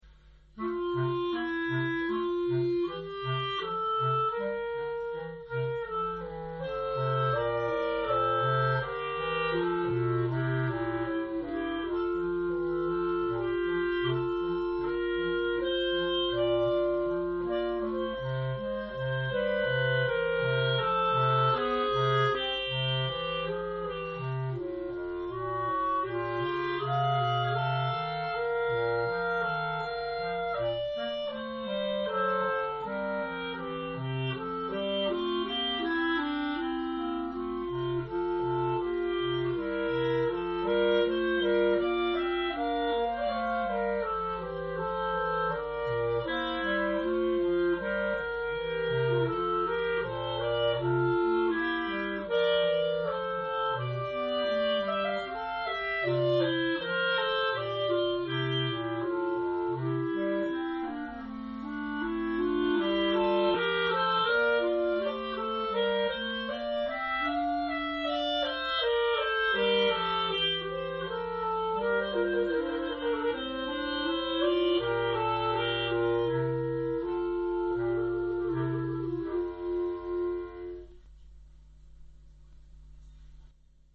Voici quelques pièces moins connues pour ensemble de clarinettes, cors de basset ou clarinettes de basset.
pour 2 clarinettes et clarinette basse (1'23)
clarinettes